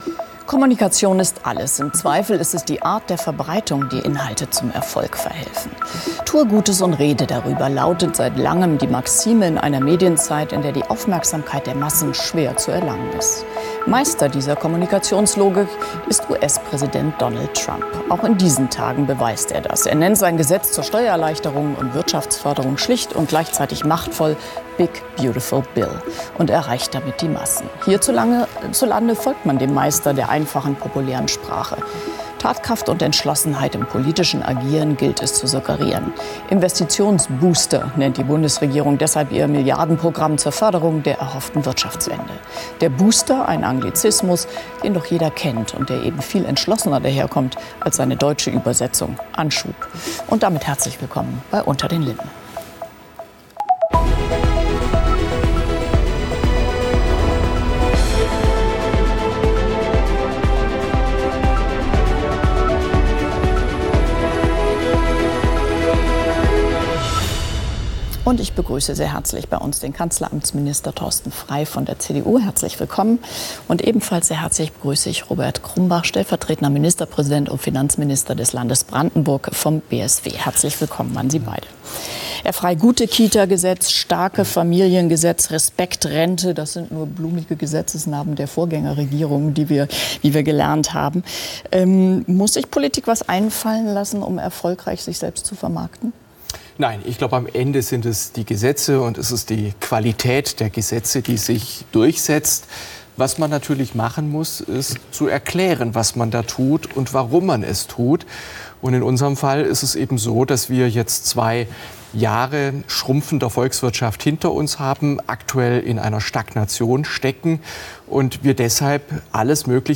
„unter den linden“ ist das politische Streitgespräch bei phoenix.
Die Diskussionen sind kontrovers, aber immer sachlich und mit ausreichend Zeit für jedes Argument. Sie vertiefen das Verständnis für aktuelle Debatten und Themen in der Politik.